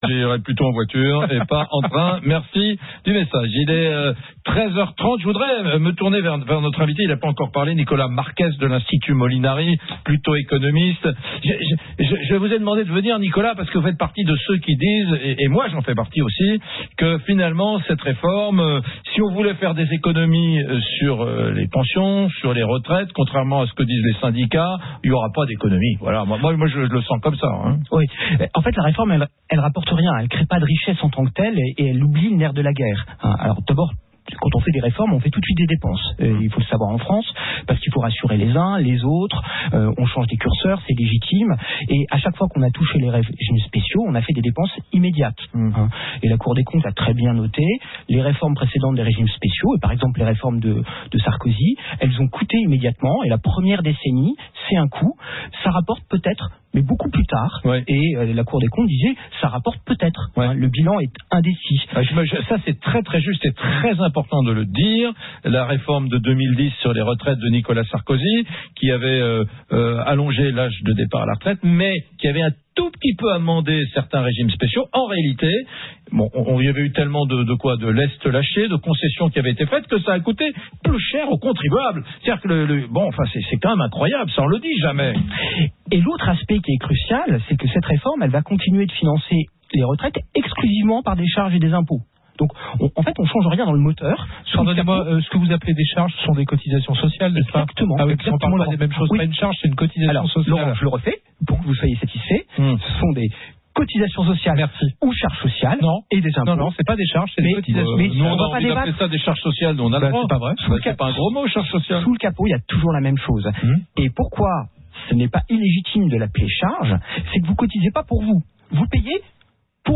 Un échange animé avec Eric Brunet